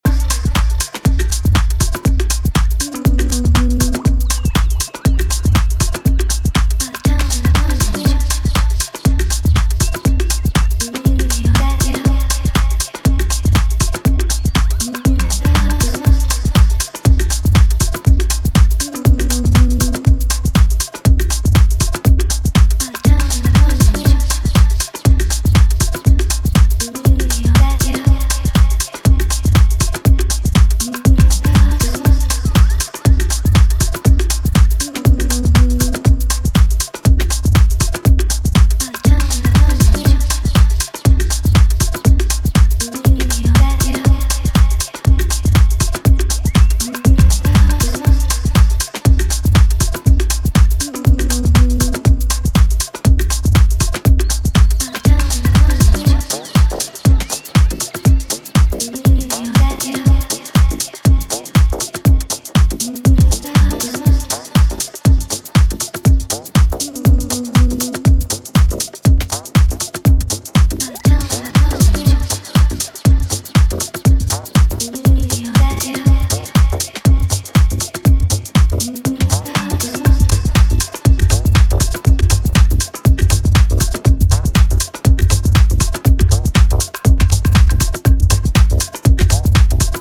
ダビーな声ネタを交えた見事なハメ度のパーカッシヴ・ミニマル